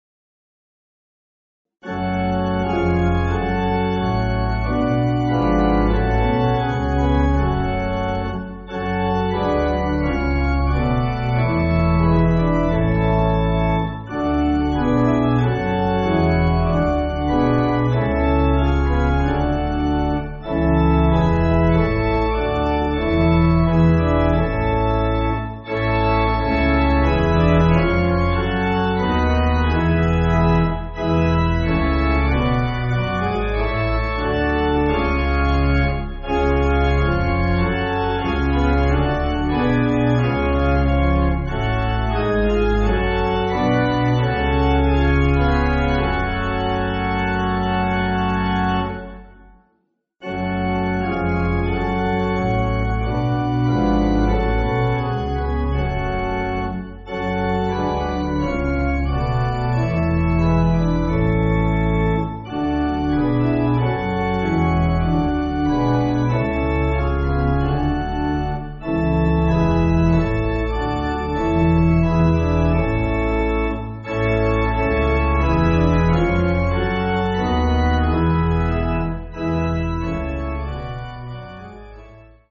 Organ
(CM)   4/F#m